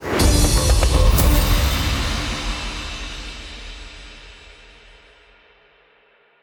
megawin_end.wav